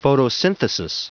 Prononciation du mot photosynthesis en anglais (fichier audio)
Prononciation du mot : photosynthesis